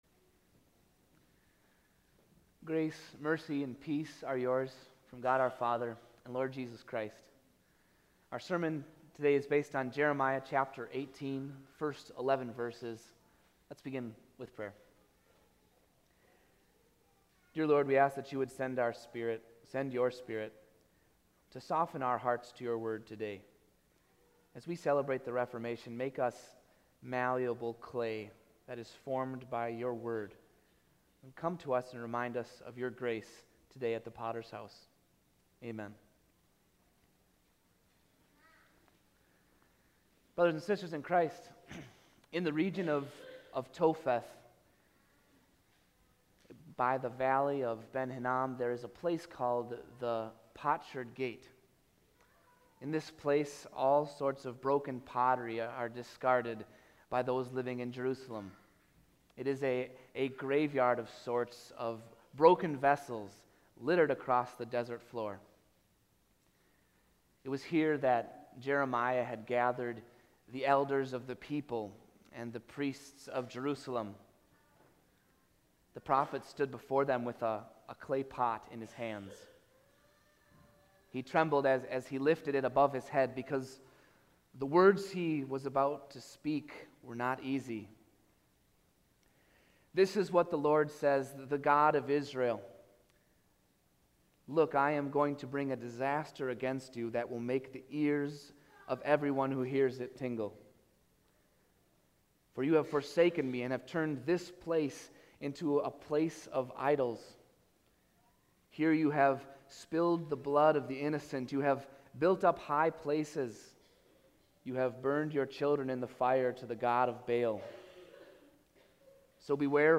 AudioSermonNovember42018.mp3